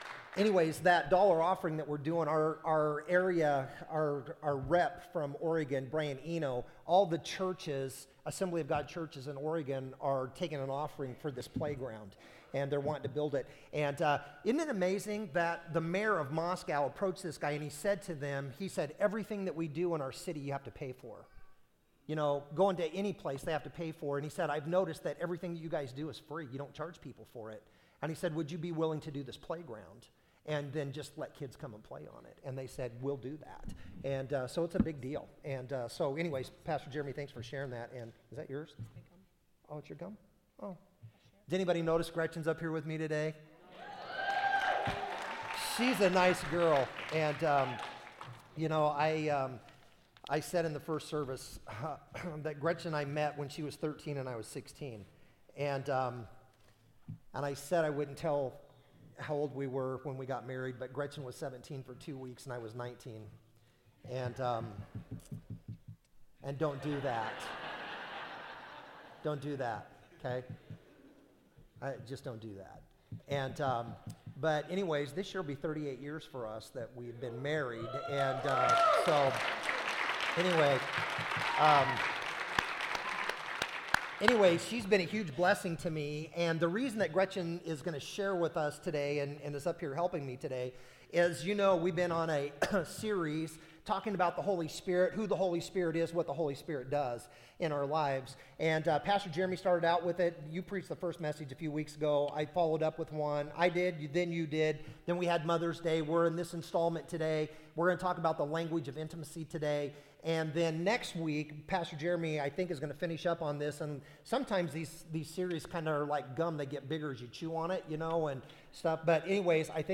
Sermons - Redmond Assembly of God